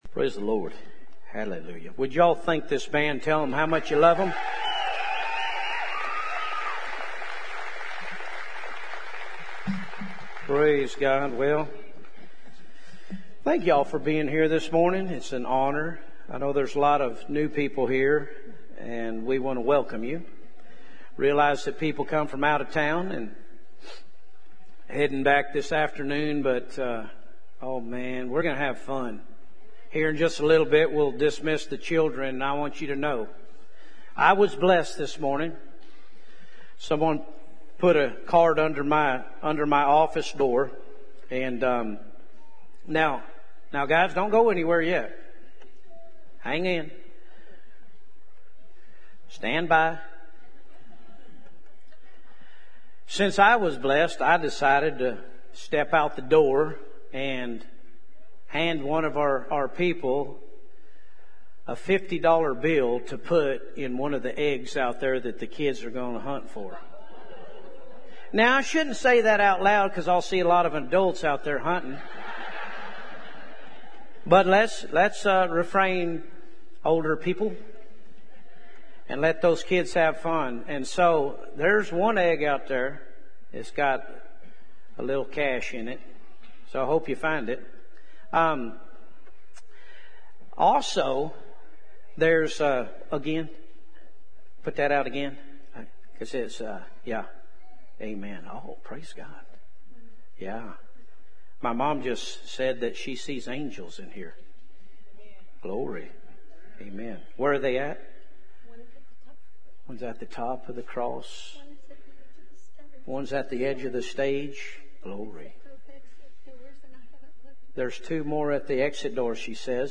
Live Stream Our Complete Service Sunday mornings at 10:30am Subscribe to our YouTube Channel to watch live stream or past Sermons Listen to audio versions of Sunday Sermons